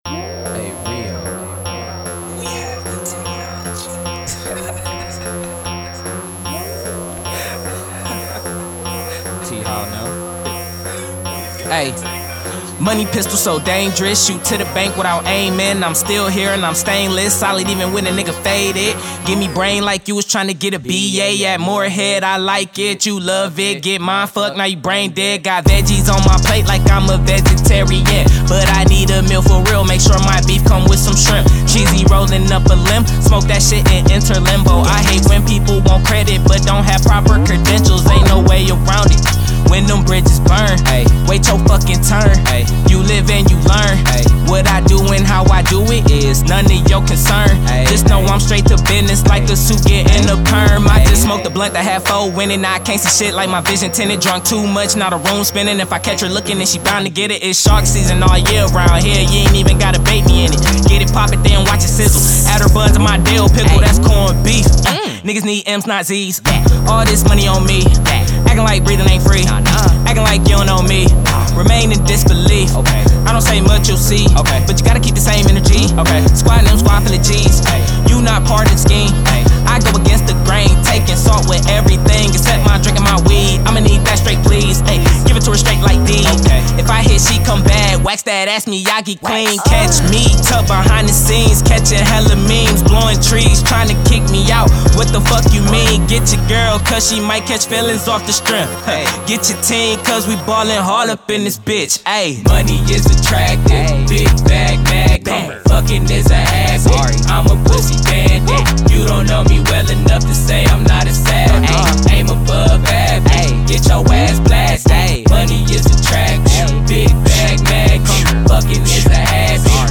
Hiphop
Description : High energy trap with clever lyrics